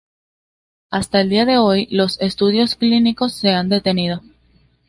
Pronúnciase como (IPA)
/ˈan/